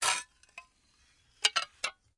玻璃板刮削 " gps17
描述：小玻璃板被相互刮擦着。 粗糙的，磨擦的声音。 用Rode NT5s在XY配置中进行近距离录音。 修剪，去掉直流电，归一化为6dB。
标签： 玻璃 光栅 嘈杂 粗糙
声道立体声